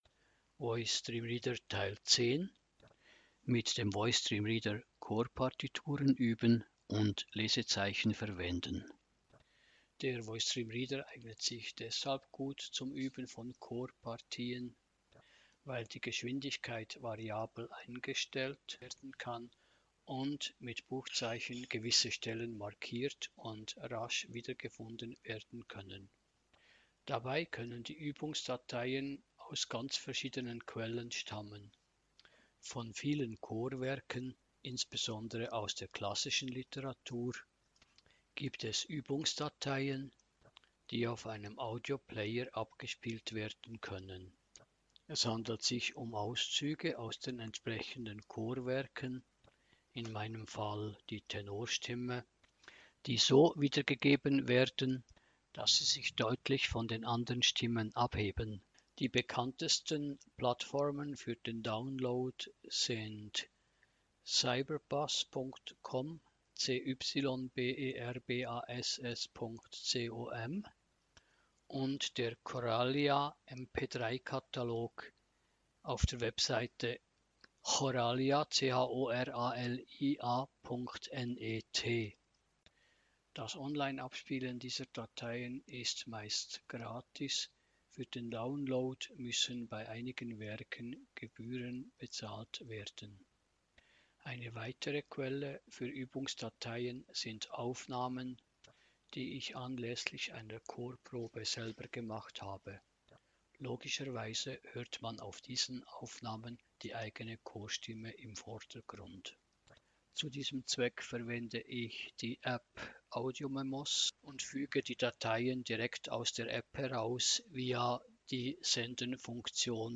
Eine gesprochene Anleitung mit zahlreichen Anwendungsbeispielen.